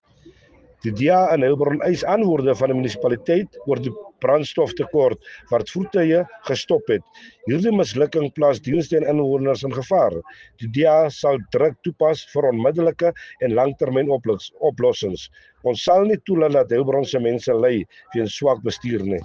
Afrikaans soundbites by Cllr Robert Ferendale and Sesotho soundbite by Cllr Joseph Mbele.